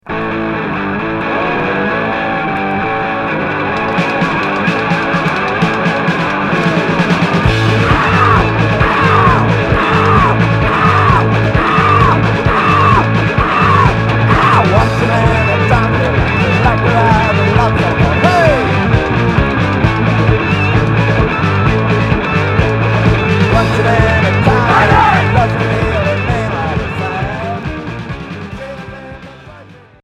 Noisy rock